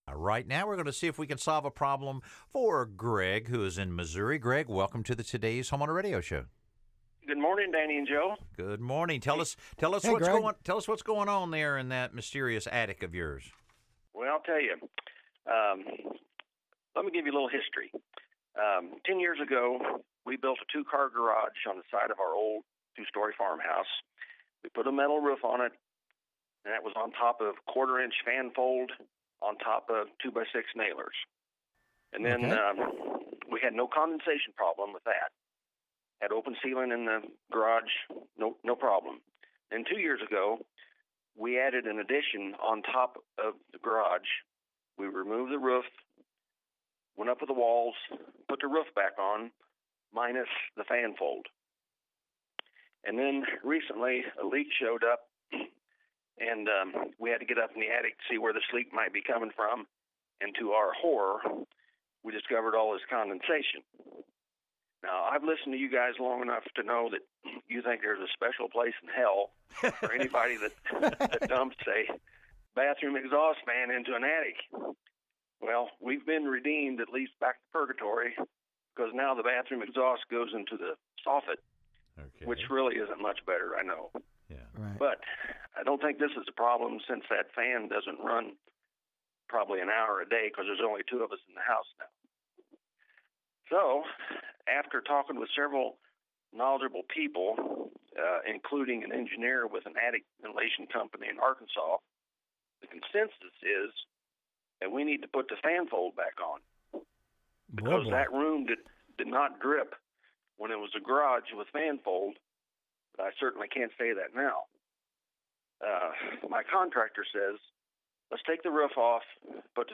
Here’s a question from our Dec. 14 broadcast: